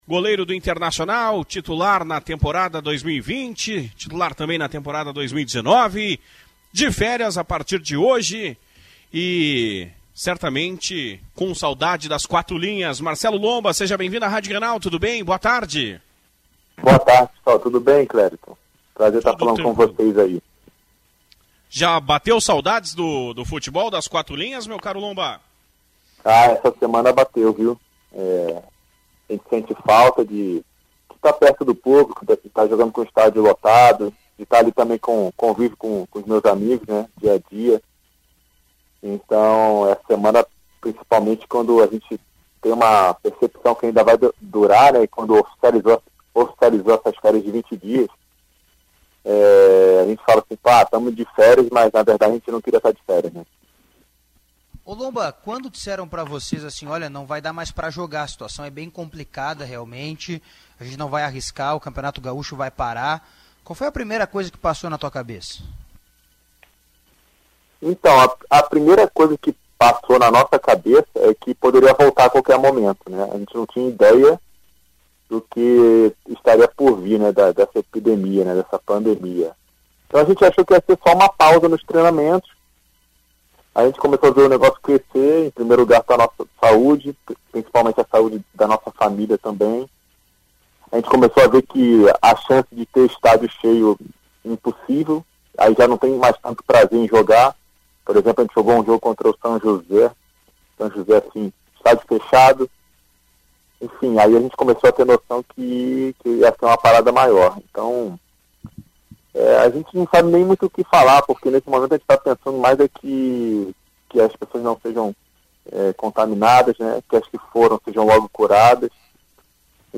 o arqueiro contou em entrevista exclusiva à Rádio Grenal detalhes do seu confinamento e também ressaltou o trabalho feito pelo capitão D’Alessandro para ajustar os vencimentos dos atletas.